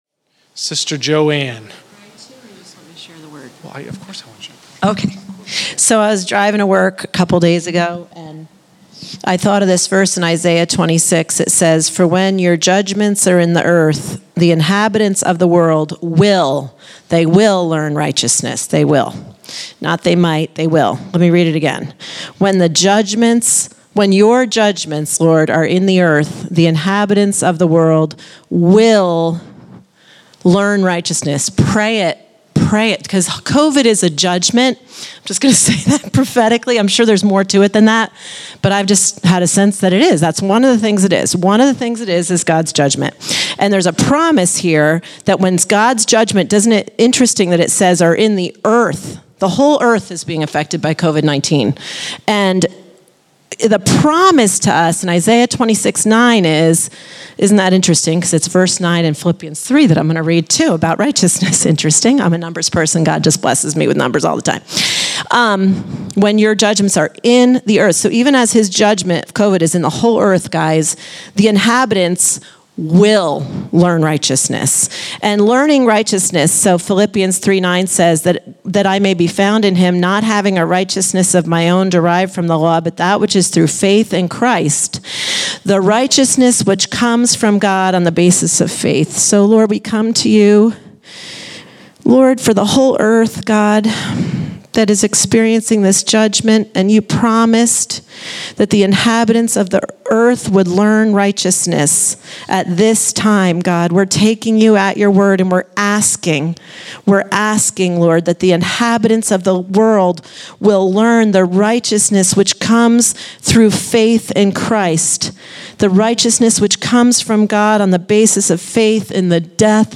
Category: Scripture Teachings      |      Location: El Dorado